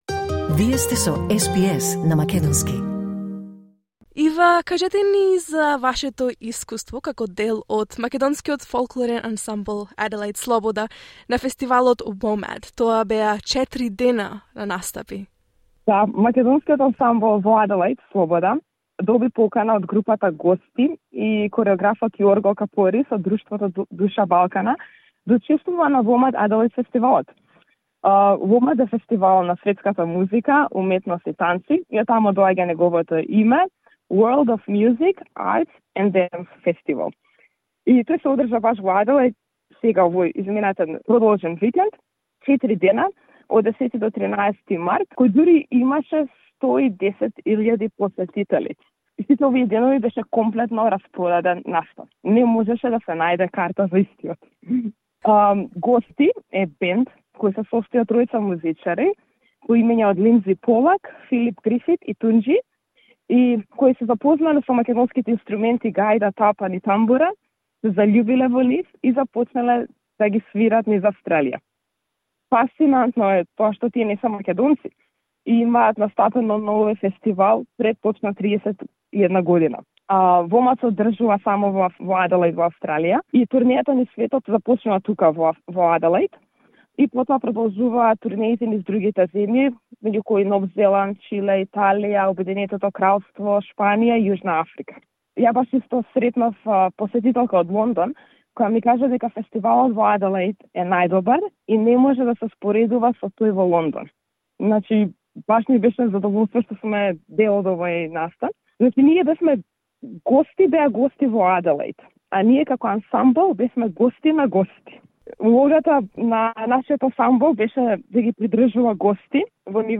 The iconic open-air festival WOMADelaide has returned to South Australia's capital for 2023, and this year among the sold out crowds rang out the sounds of the gaida, tambura and tapan accompanied by The Adelaide Macedonian folkloric ensemble, Sloboda.